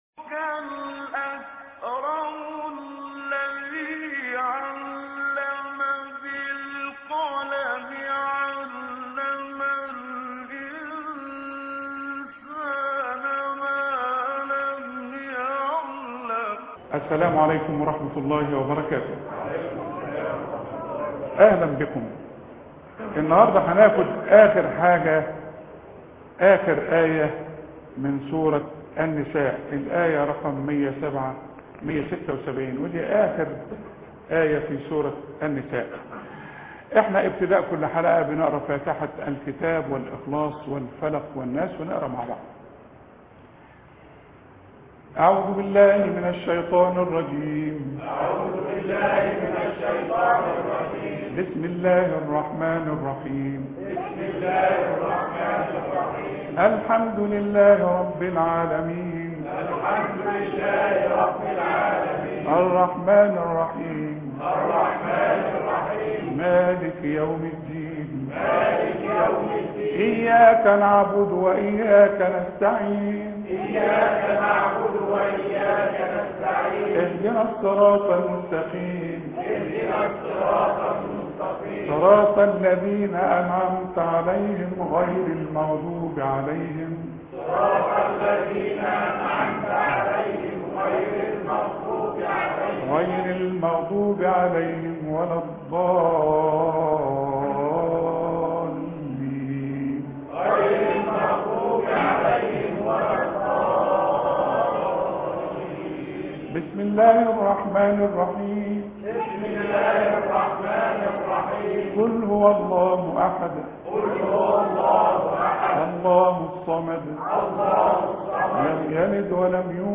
قراءة من الاية 176 فى سورة النساء (3/8/2014 ) إقرأ كتابك